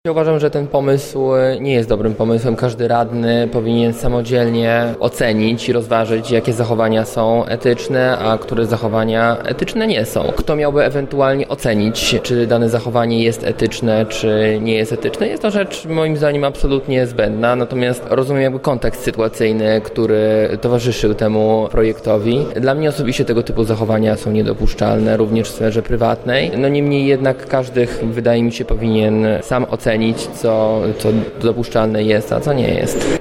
Przeciwny kodeksowi jest radny Mateusz Zaczyński z Platformy Obywatelskiej, który wskazuje na to, że radni powinni sami myśleć: